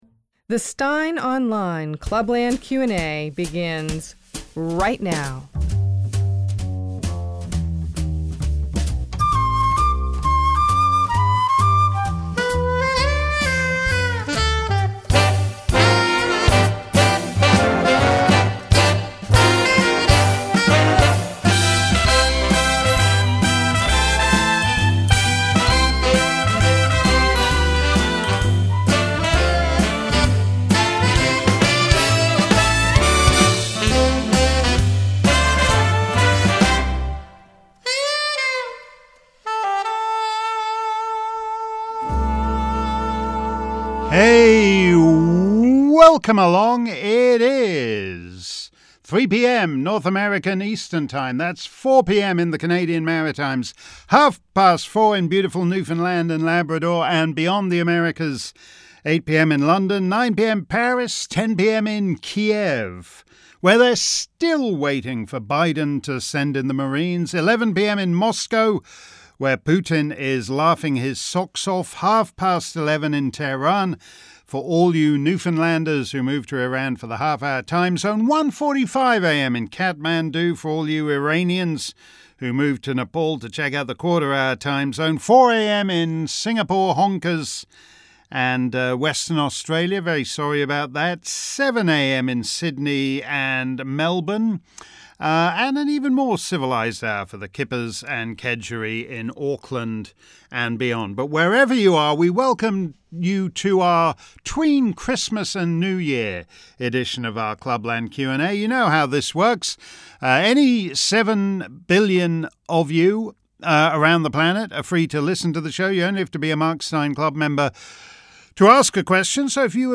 If you missed today's edition of our Clubland Q&A live around the planet, here's the action replay.